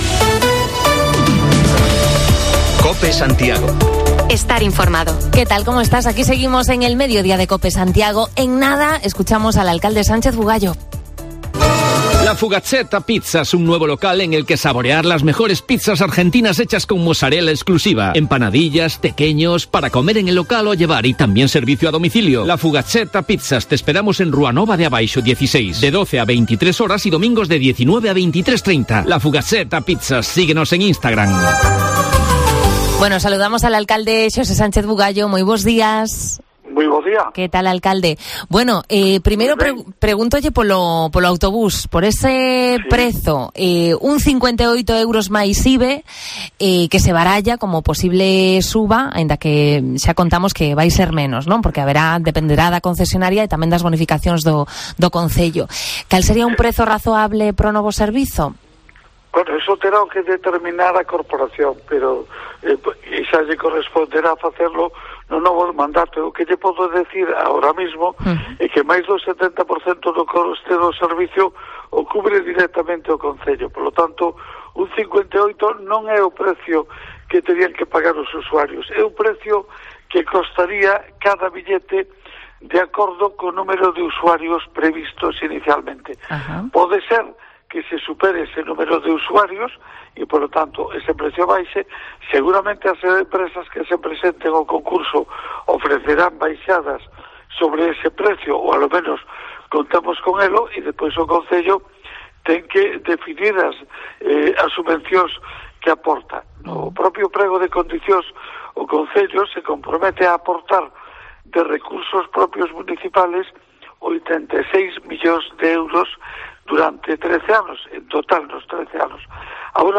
Entrevista con el Alcalde Sánchez Bugallo. Charlamos sobre la vivienda vacía en Santiago, el nuevo servicio de transporte urbano y las cuestiones que nos han trasladado desde San Lourenzo y preguntas por el uso libre de pabellones municipales